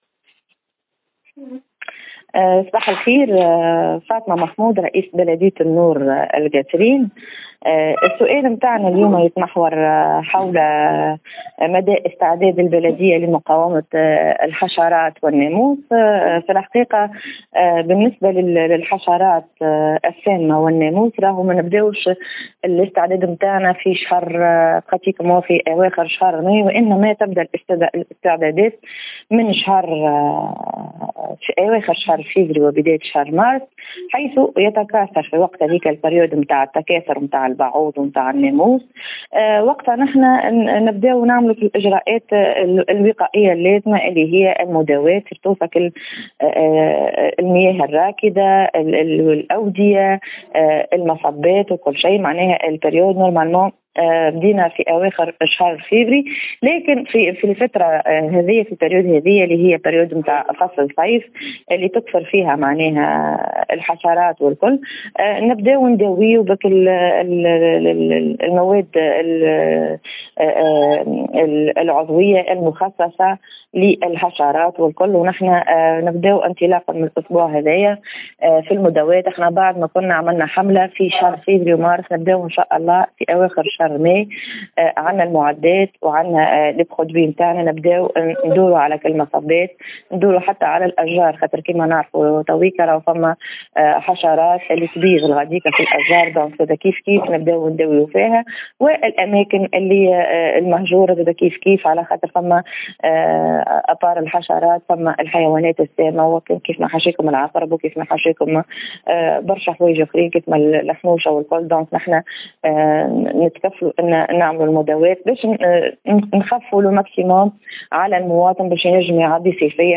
La maire d’Ennour relevant du gouvernorat de Kasserine a déclaré à Tunisie Numérique que les campagnes de lutte contre les moustiques ont démarré à la fin du mois de février, la période de reproduction des insectes précisant qu’elle sera intensifiée d’ici le début de la saison estivale.